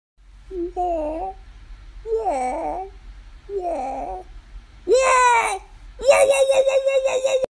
Crash Bandicoot Woah Sound
meme